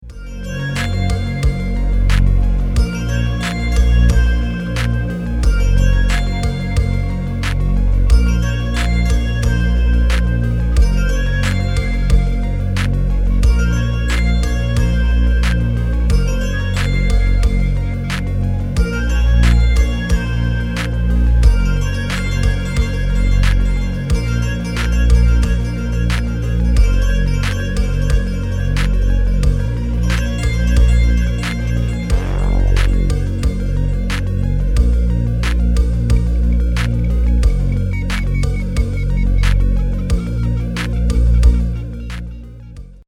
Antworte der Maschine, und lade Dir hier den Klingelton der Maschine runter! 😉
Marsmännchenmaschine-Klingelton.mp3